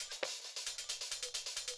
KIN Beat - Perc.wav